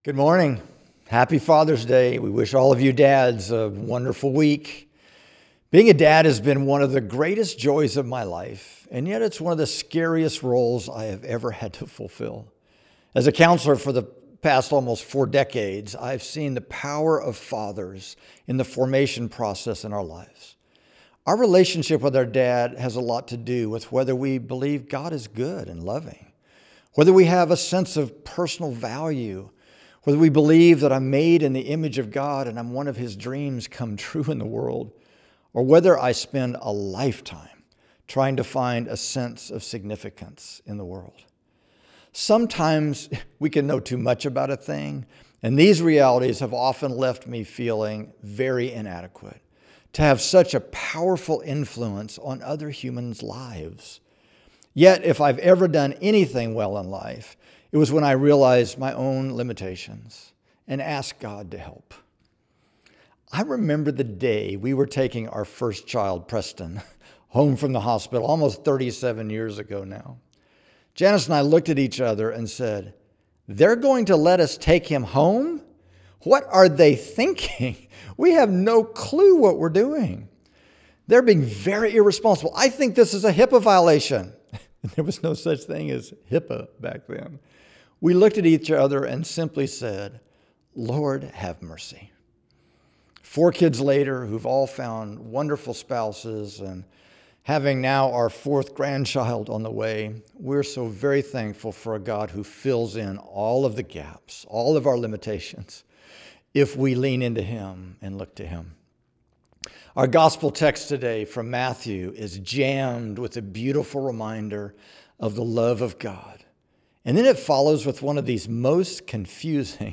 Weekly sermon podcasts from Sanctuary Church in Tulsa, OK